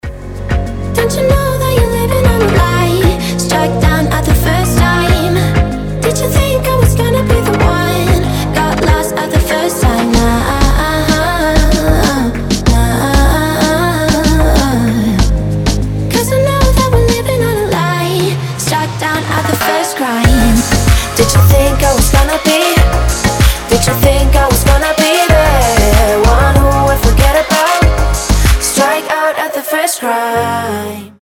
• Качество: 320, Stereo
поп
мелодичные
tropical house
красивый женский голос
Теплая музыка в стиле поп, тропикал хаус.